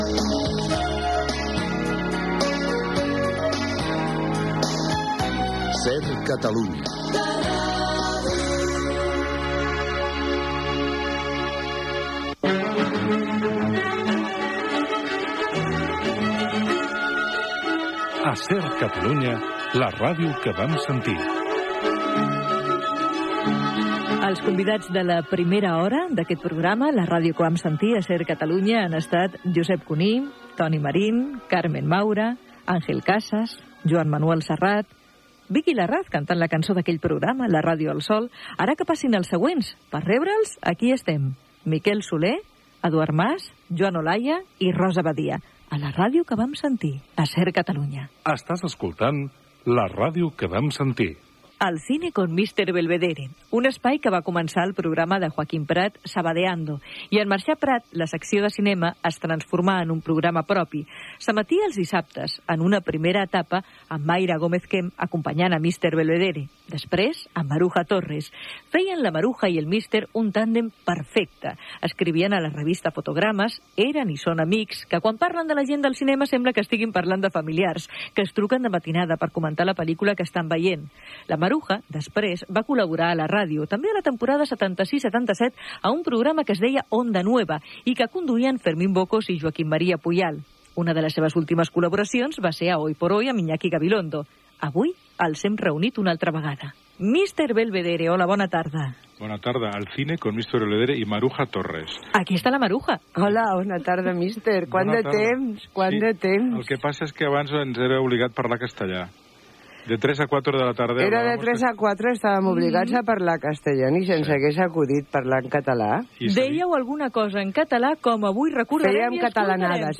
Indicatiu de l'emissora i del programa, equip. Entrevista a Maruja Torres i Jaume Figueras, presentadors, la dècada de 1970 , del programa "Al cine con Mr. Belvedere"
Divulgació